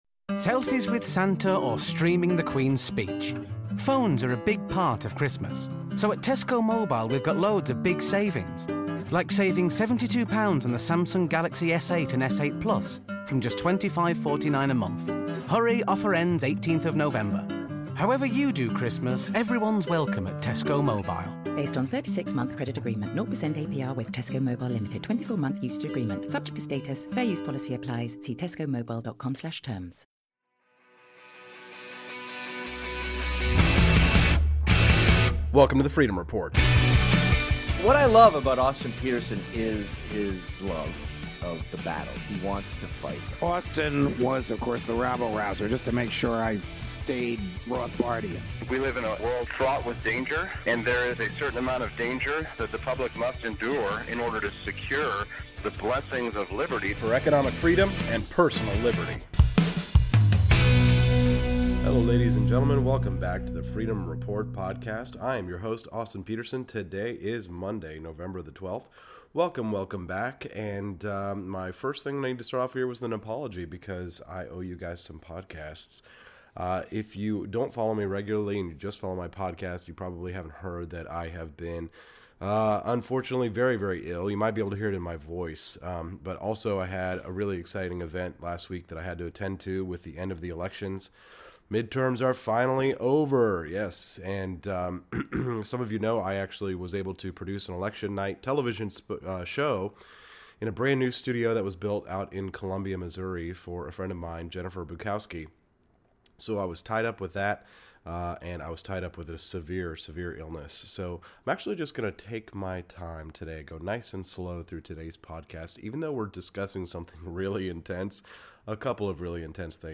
and plays a sound recording from the moment that the guns ceased firing.